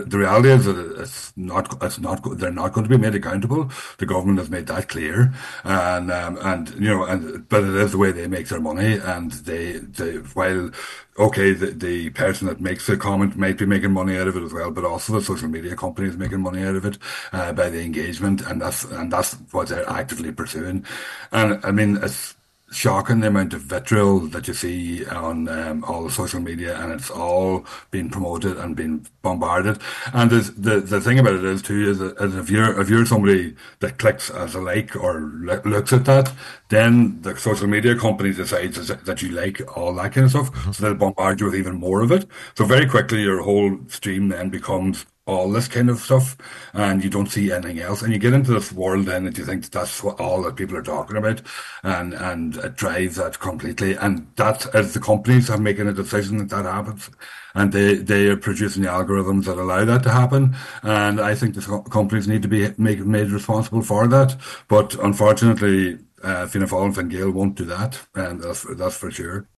former Independent TD Thomas Pringle says these companies must be held to account: